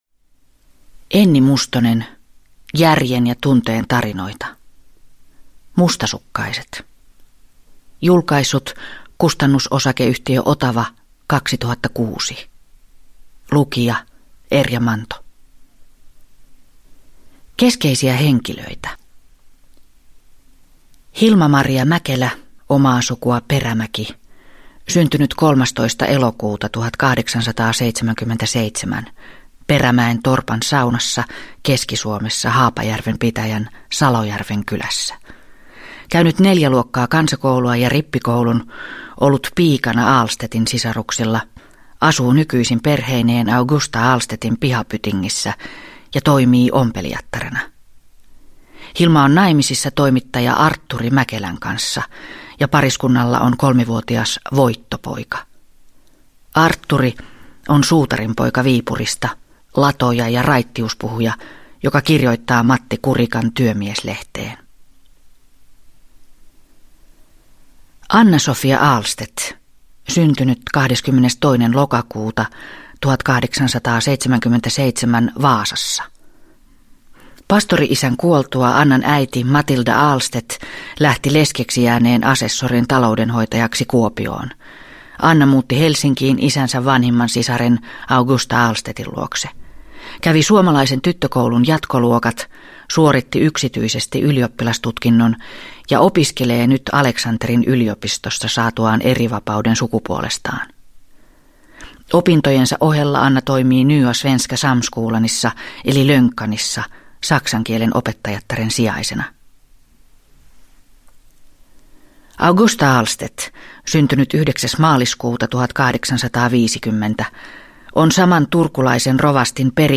Mustasukkaiset – Ljudbok – Laddas ner